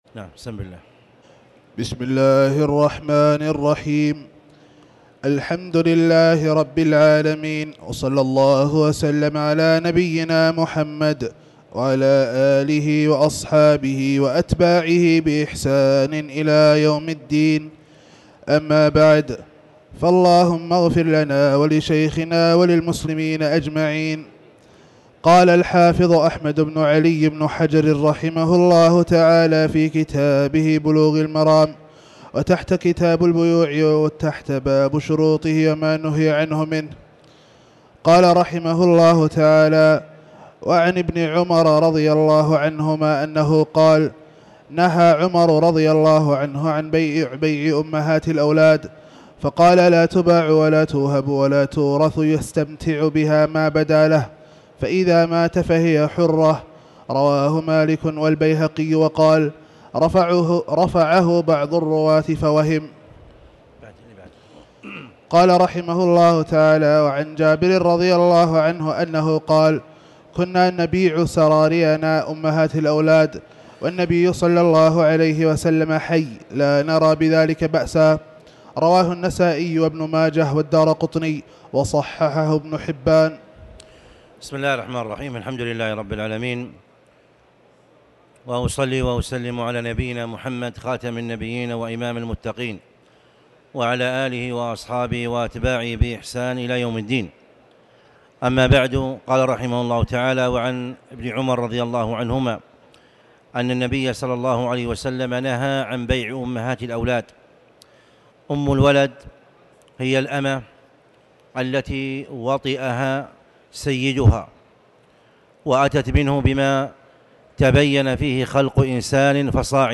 تاريخ النشر ٢٥ محرم ١٤٤٠ هـ المكان: المسجد الحرام الشيخ